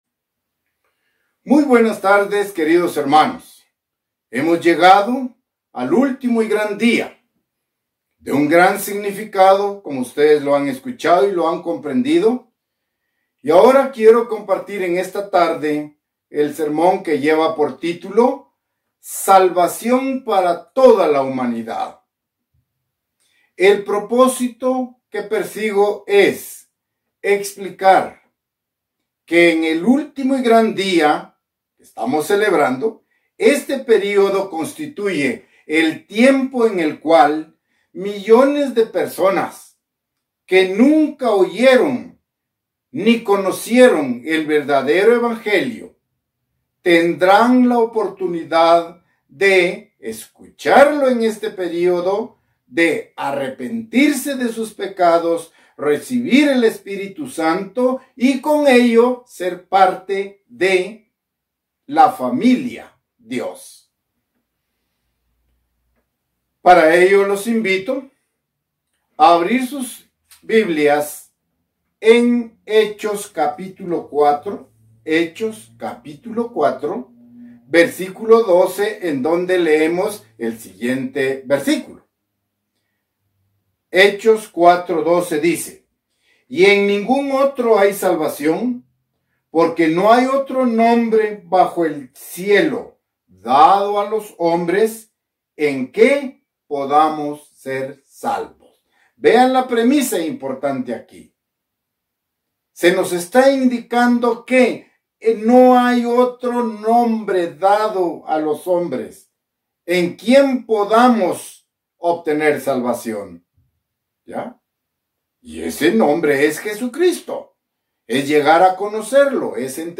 Mensaje entregado el 28 de septiembre de 2021.
Given in Ciudad de Guatemala